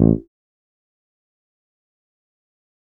A THUMB.wav